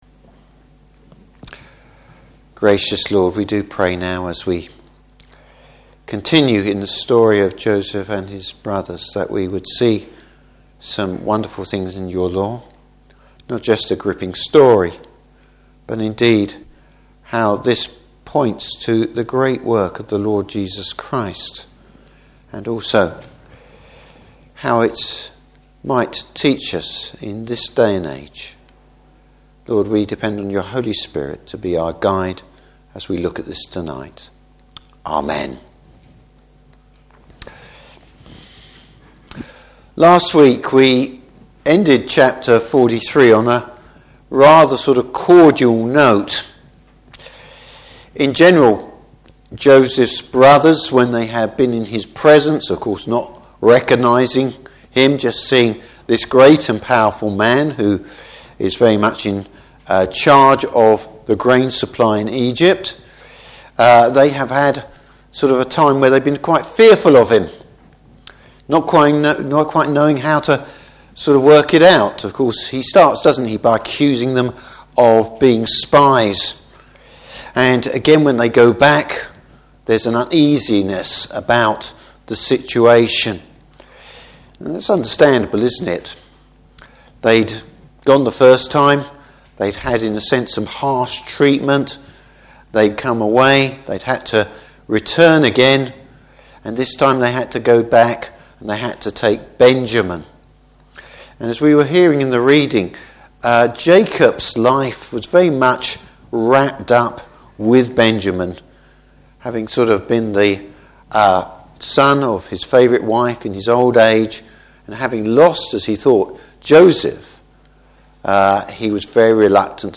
Service Type: Evening Service Joseph reveals his identity to his brothers.